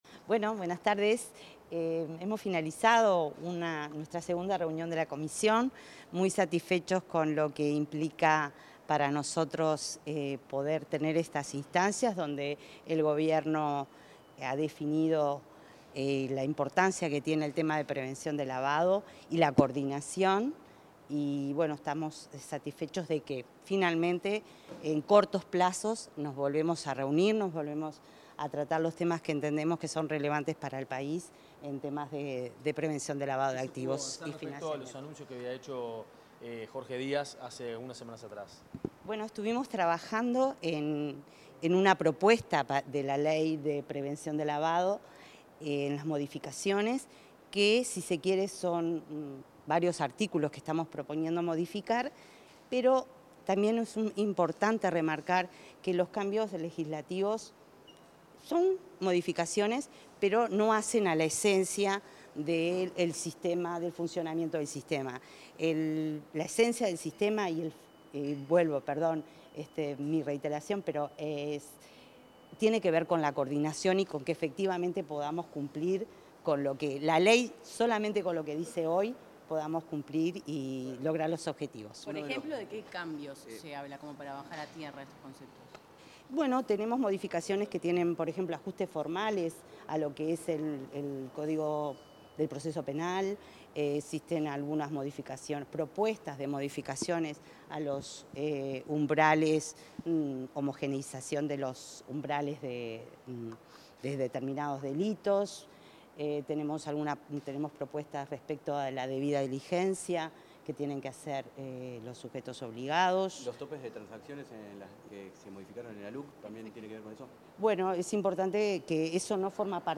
Declaraciones de la secretaria nacional de la Senaclaft, Sandra Libonatti
Declaraciones de la secretaria nacional de la Senaclaft, Sandra Libonatti 08/05/2025 Compartir Facebook X Copiar enlace WhatsApp LinkedIn La titular de la Secretaría Nacional para la Lucha contra el Lavado de Activos y la Financiación del Terrorismo (Senaclaft), Sandra Libonatti, dialogó con los medios de prensa, tras la segunda reunión de la Comisión contra el Lavado de Activos.